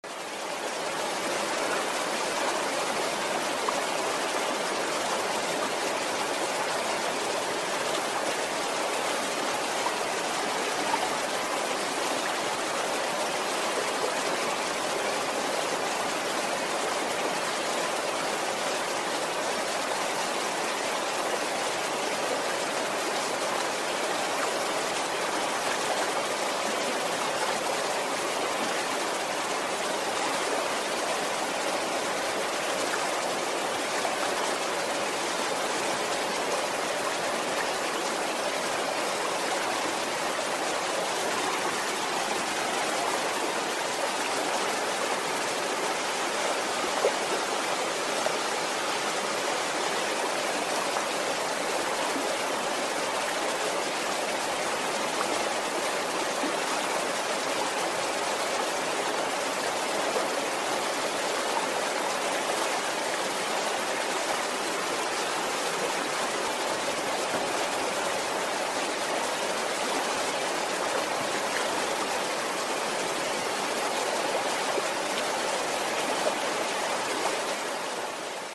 Som de Cachoeira.mp3